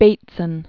(bātsən), William 1861-1926.